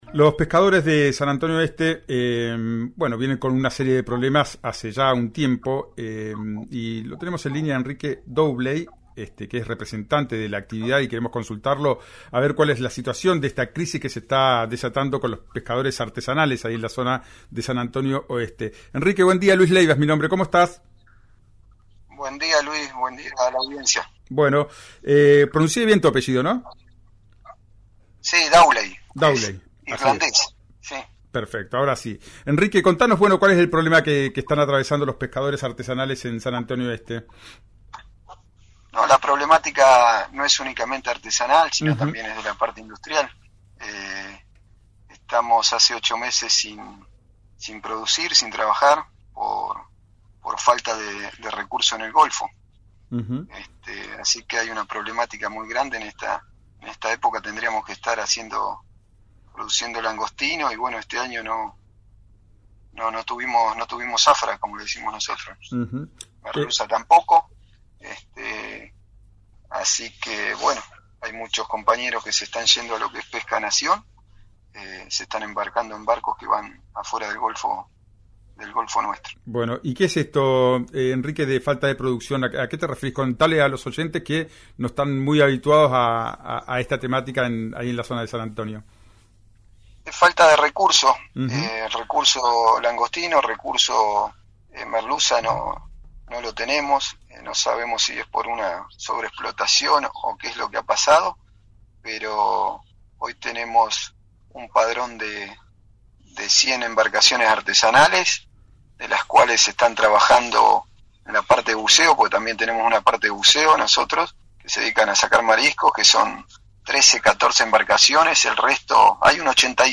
En este caso, el trabajador dialogó con “Ya es tiempo”, por RÍO NEGRO RADIO.